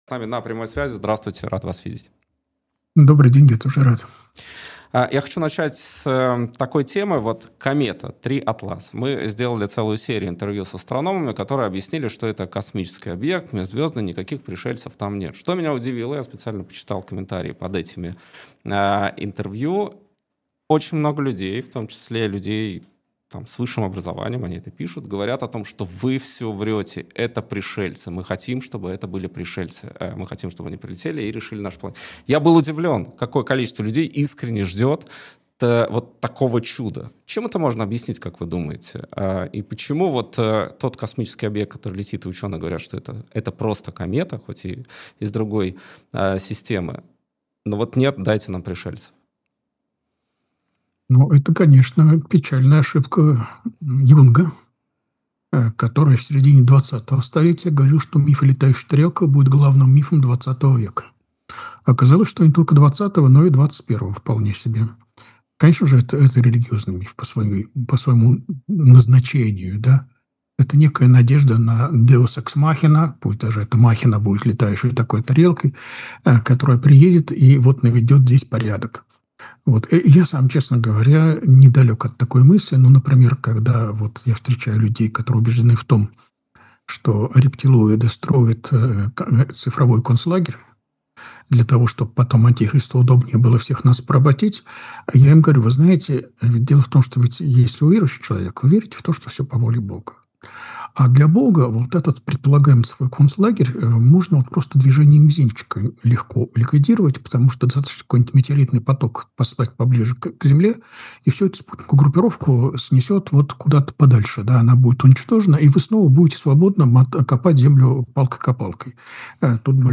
Андрей Кураев богослов